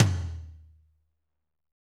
Index of /90_sSampleCDs/Northstar - Drumscapes Roland/DRM_Medium Rock/TOM_M_R Toms x
TOM M R M0FR.wav